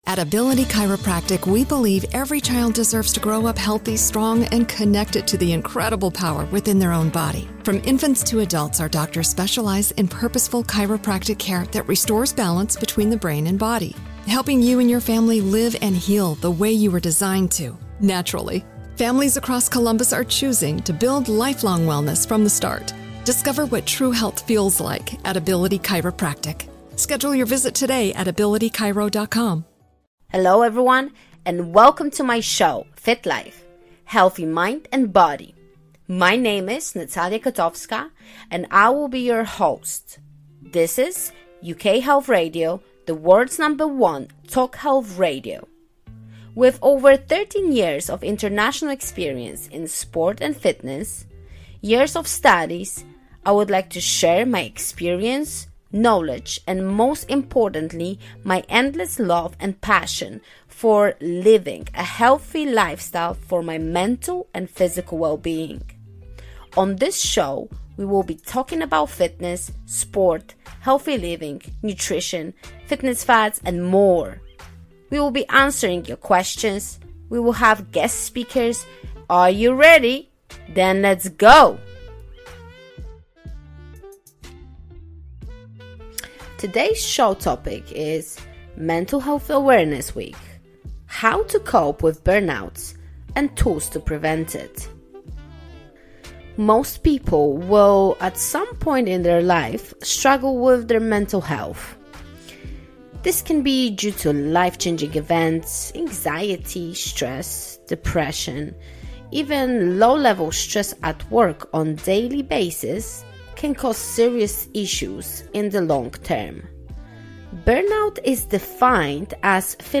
Your questions are answered by experts, and each episode includes group exercise.